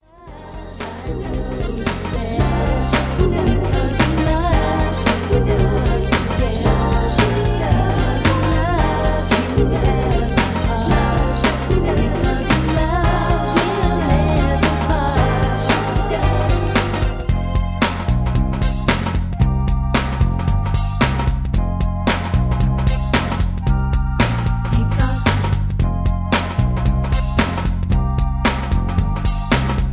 Key-Cm) Karaoke MP3 Backing Tracks
Just Plain & Simply "GREAT MUSIC" (No Lyrics).